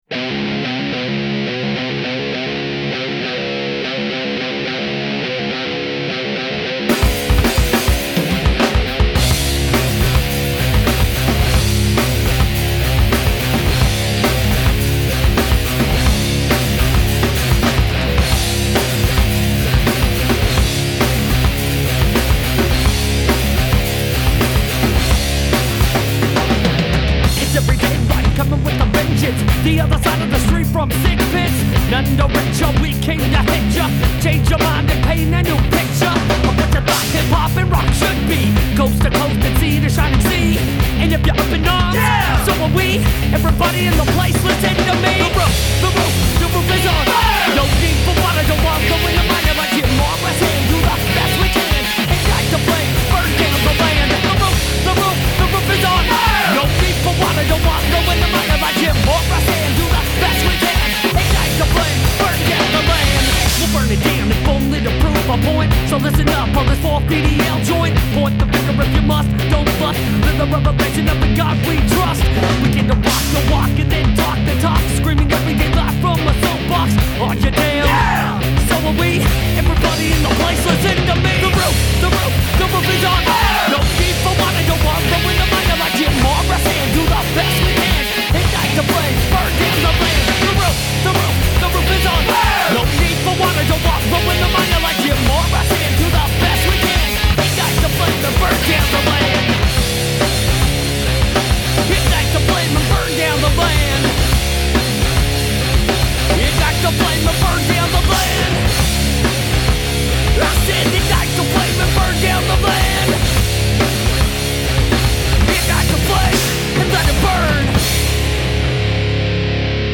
Rock, Metal, Rap